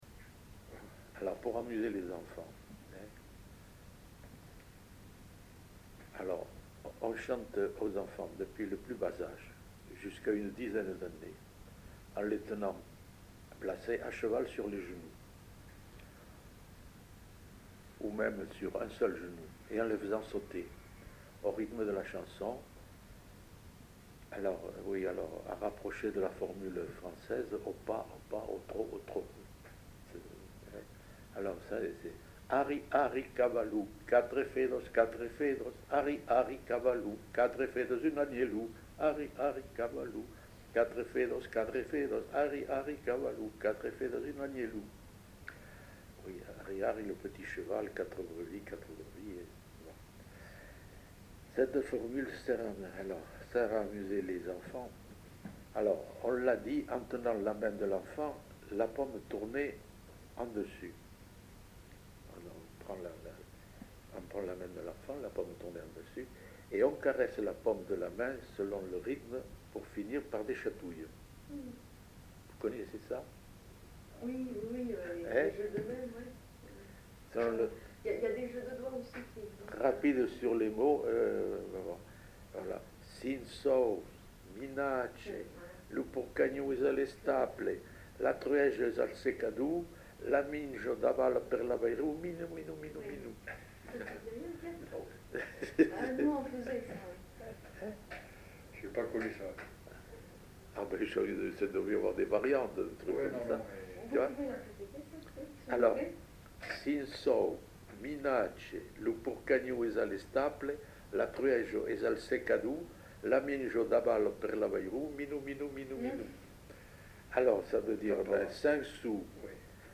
Genre : forme brève
Effectif : 1
Type de voix : voix d'homme
Production du son : chanté
Classification : formulette enfantine